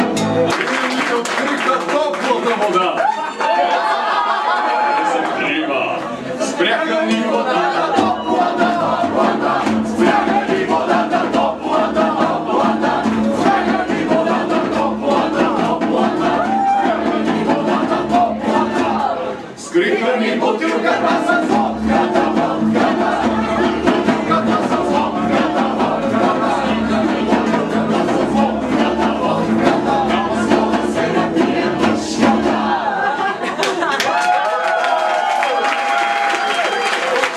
акустичния концерт